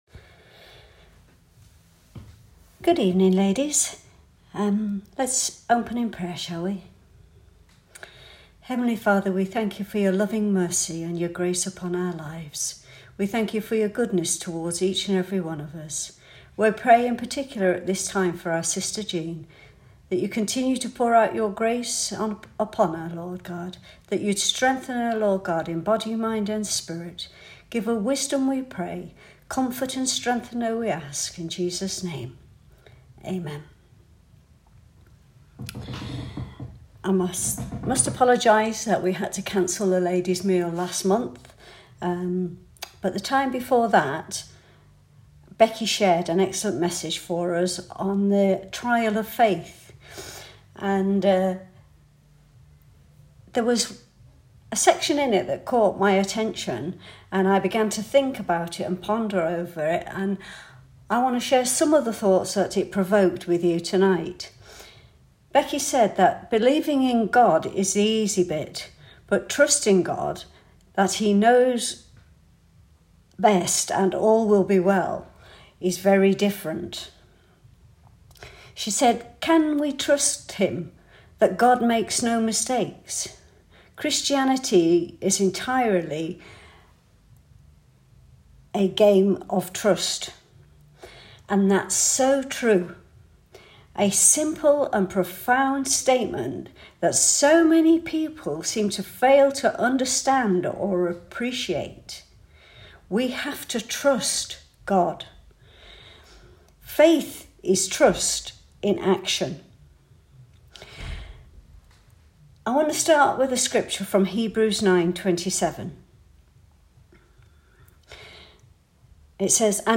Ladies Message “Trust and obey”